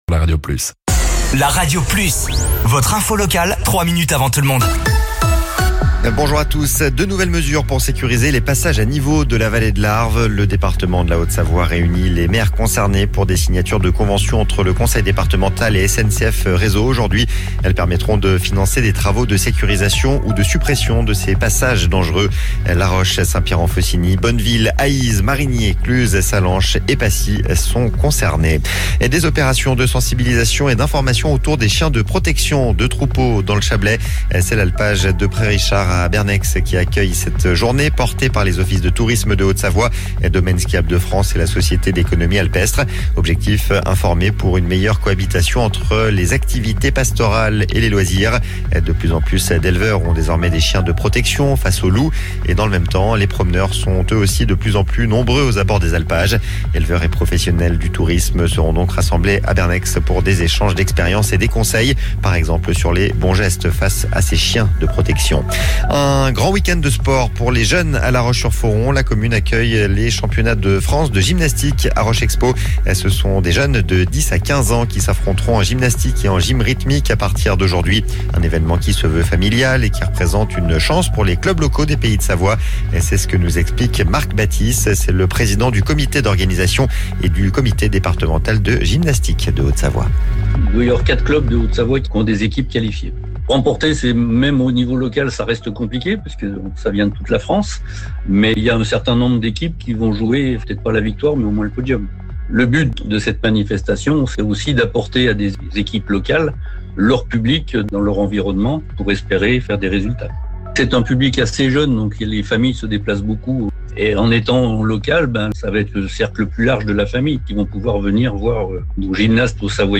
Flash Info
Votre flash info - votre journal d'information sur La Radio Plus